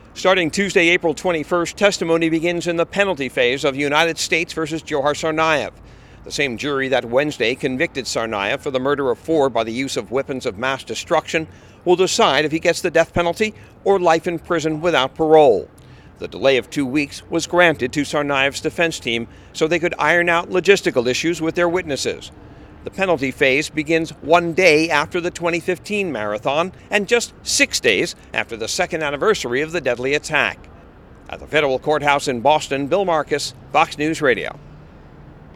HAS MORE FROM THE FEDERAL COURTHOUSE IN BOSTON.